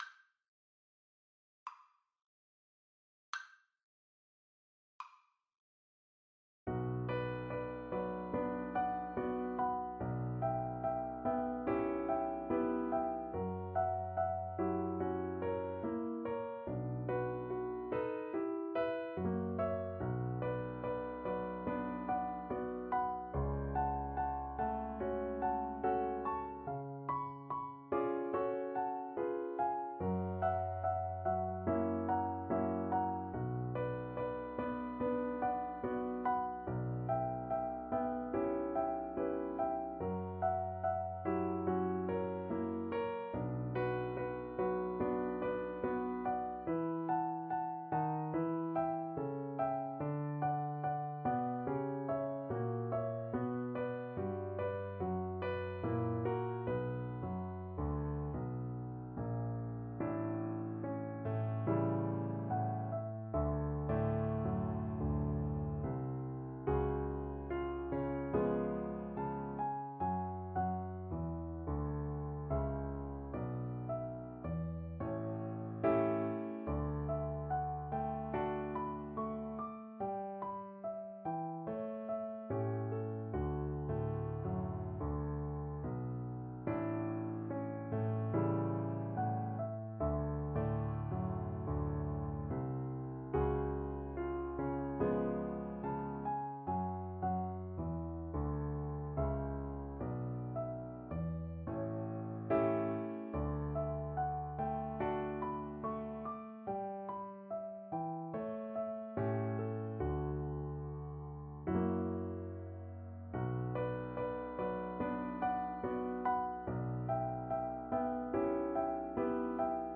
Play (or use space bar on your keyboard) Pause Music Playalong - Piano Accompaniment Playalong Band Accompaniment not yet available transpose reset tempo print settings full screen
Violin
2/4 (View more 2/4 Music)
Moderato il canto = c.56
B minor (Sounding Pitch) (View more B minor Music for Violin )
Classical (View more Classical Violin Music)
Cuban